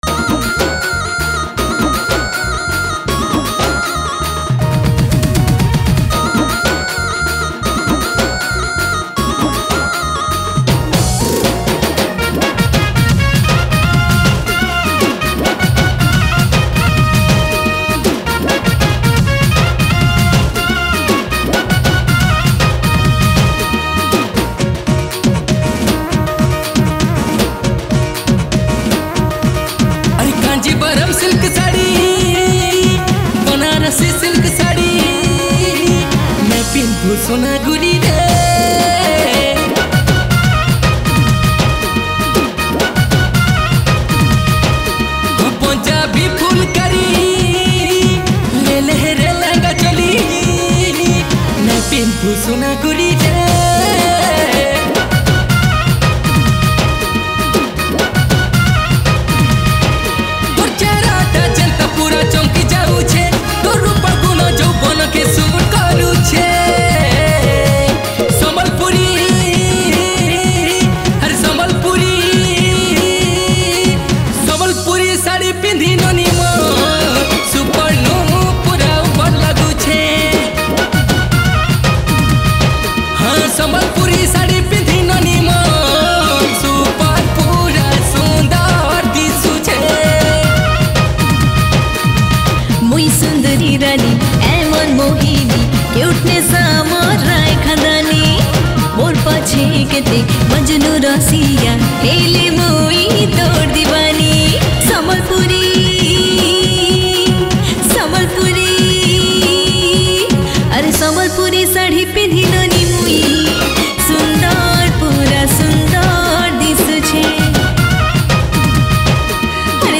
Sambapuri Single Song 2022